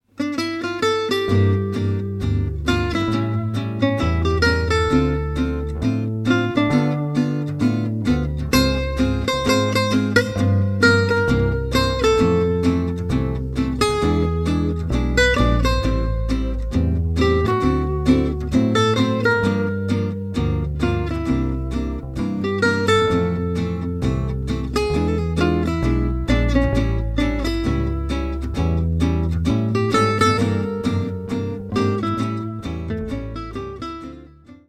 Plans d’impro dans le plus pur style Django.